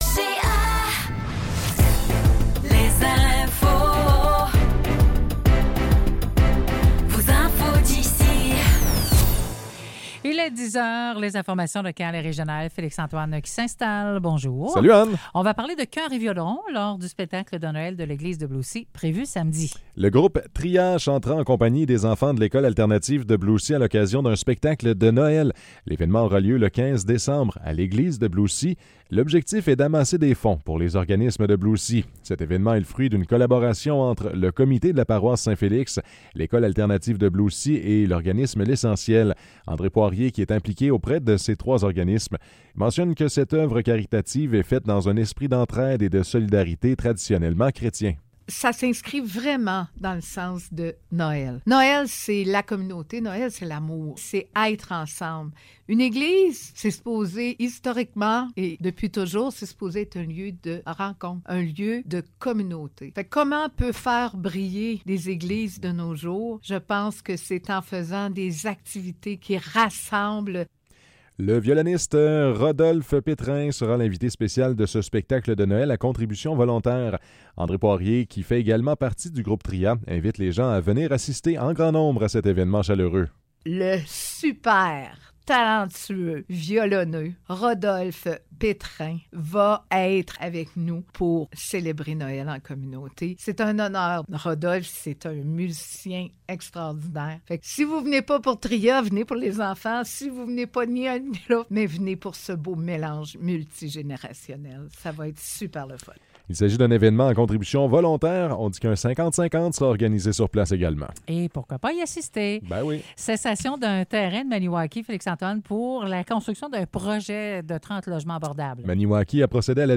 Nouvelles locales - 11 décembre 2024 - 10 h